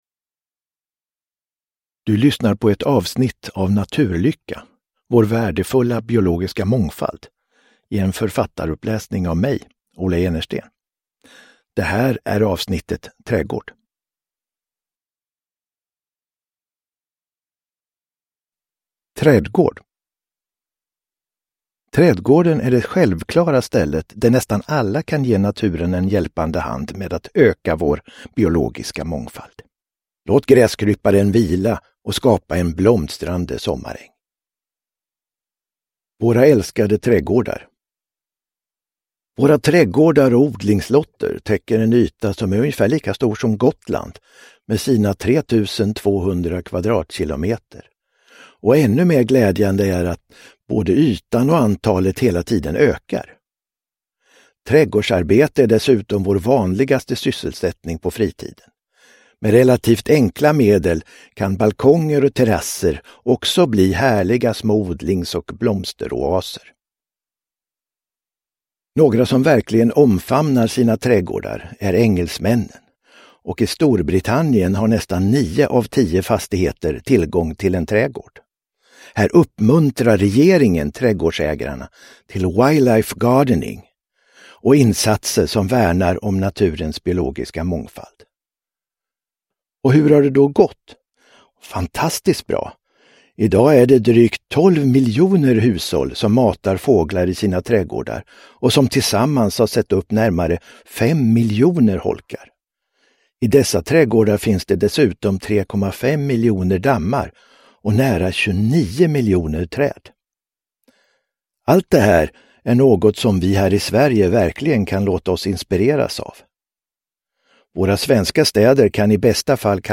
Naturlycka - Trädgård – Ljudbok – Laddas ner